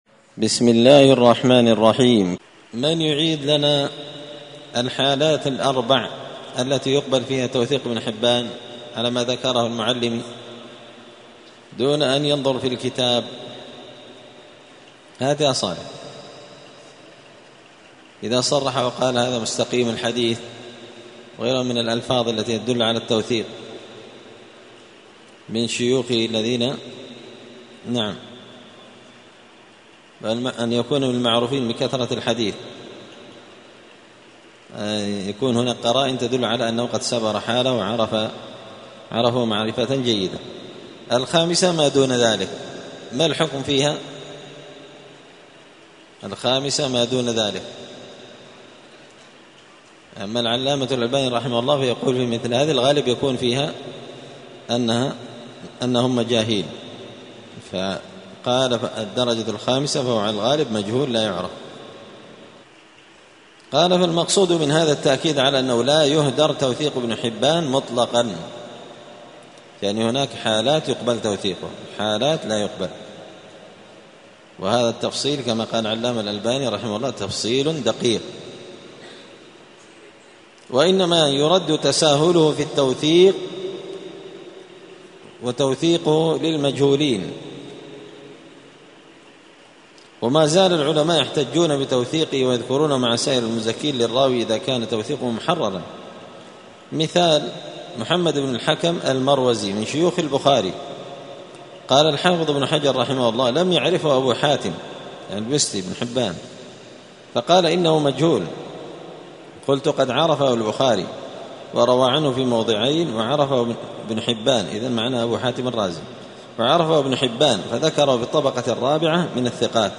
*الدرس الخامس والأربعون (45) تابع لمراتب الثقات عند ابن حبان*